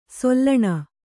♪ sollaṇa